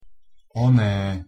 2. В разговорном опять же языке есть слово-паразит oné (произносим твердо!).
pronunciation_sk_one.mp3